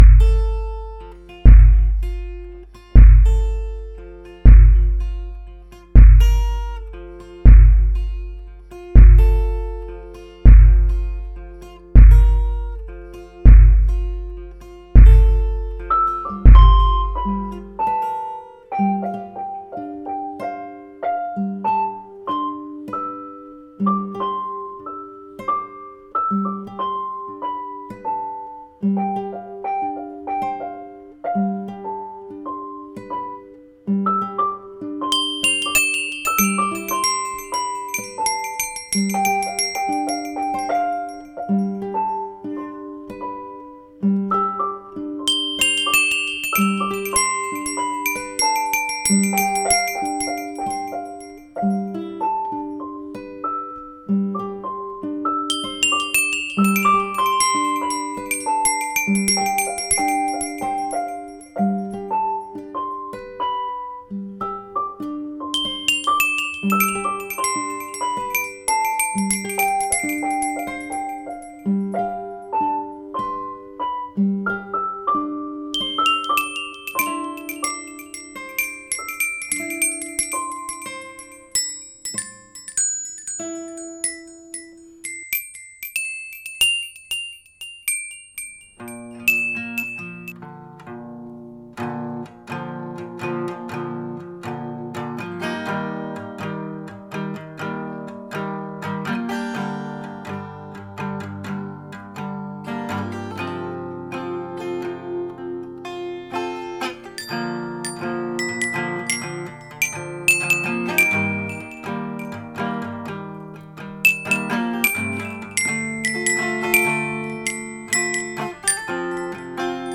esquisse-version-instumemtale.mp3